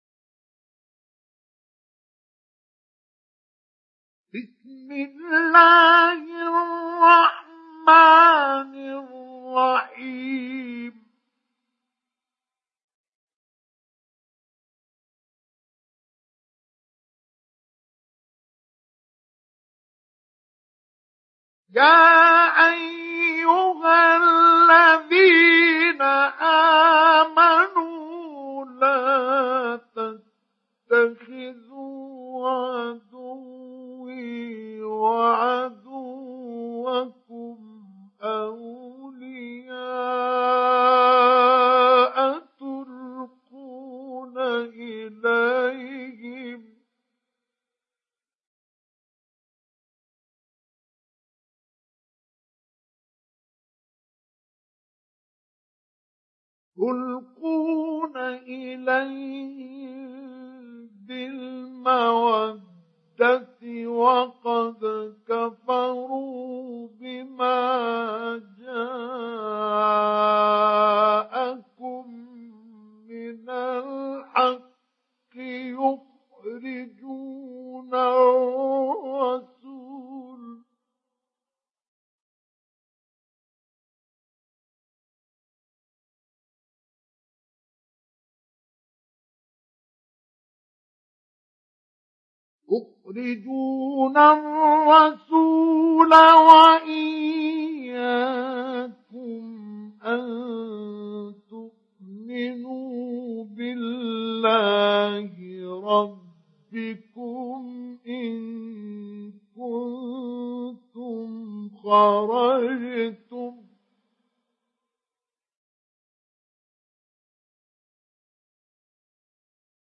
Mümtehine Suresi İndir mp3 Mustafa Ismail Mujawwad Riwayat Hafs an Asim, Kurani indirin ve mp3 tam doğrudan bağlantılar dinle
İndir Mümtehine Suresi Mustafa Ismail Mujawwad